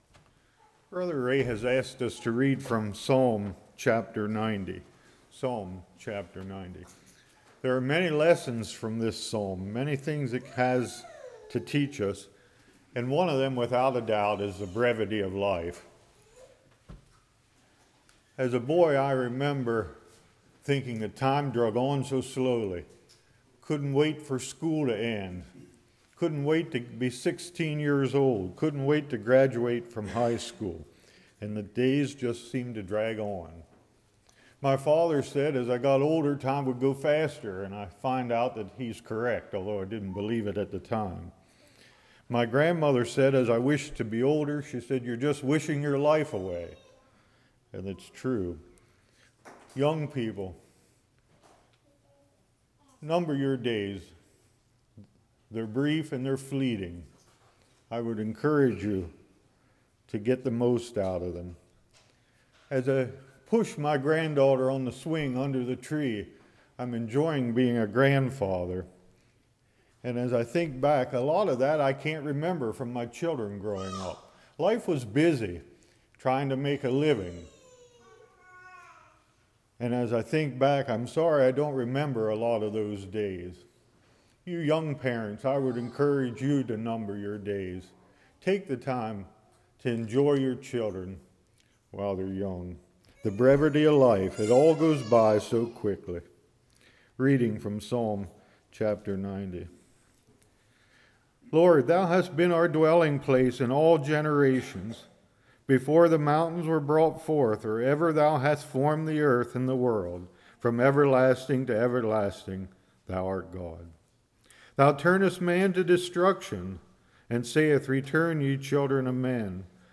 Psalms 90:1-17 Service Type: Evening Time Dwells in God God is Equally Above Everything What is Our View of God?